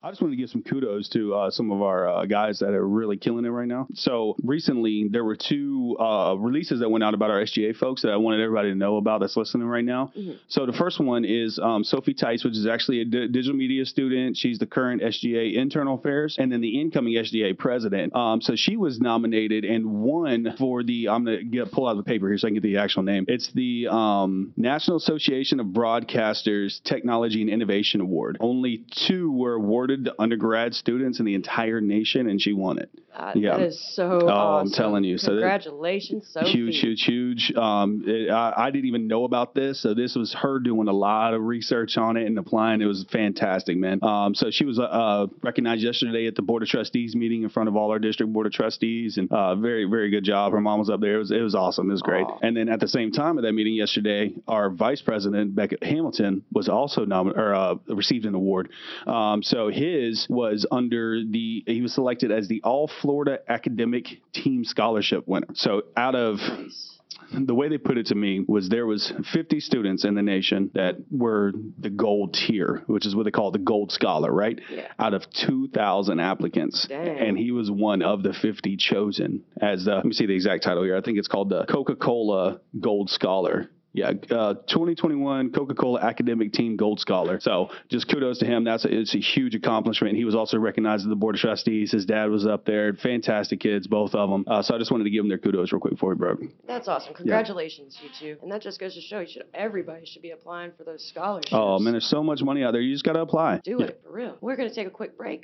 Good Morning Commodores Intro